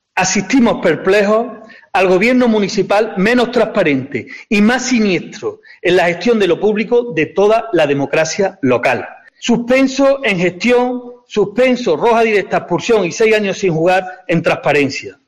Escucha al portavoz de Izquierda Unida, Pedro García